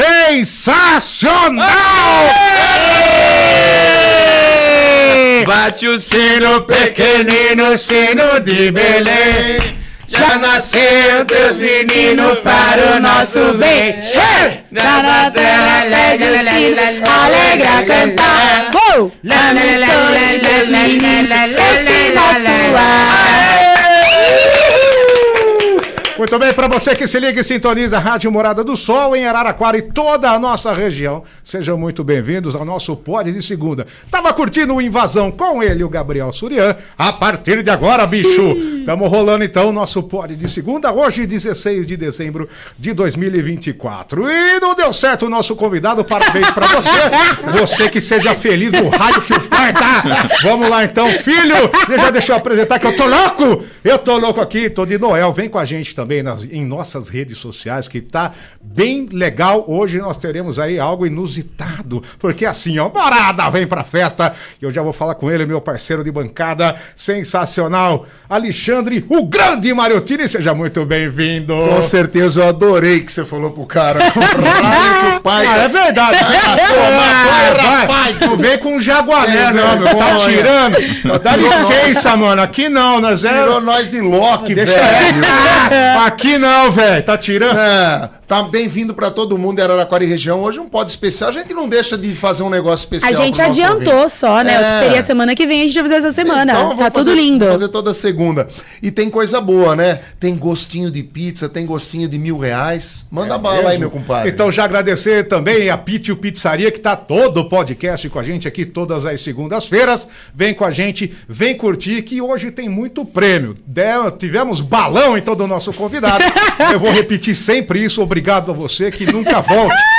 Um papo descontraído, muita música e histórias de quem vive o mundo sertanejo!